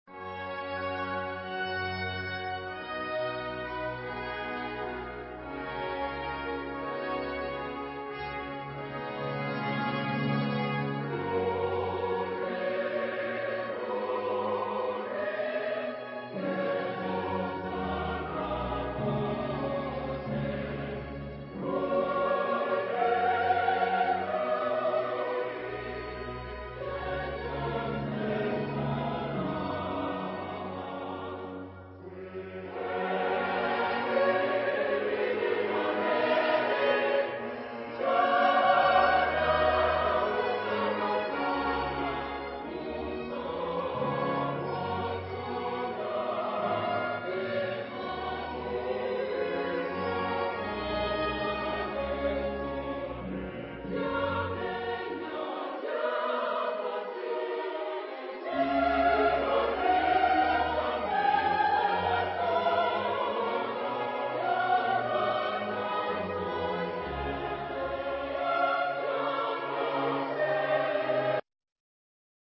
Choral setting.
Tonality: G flat major